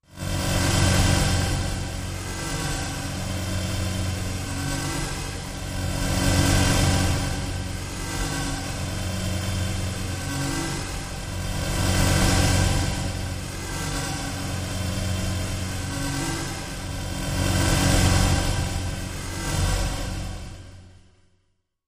Matrix Drone-Ambient low filter shifts with harsh pulsing low tone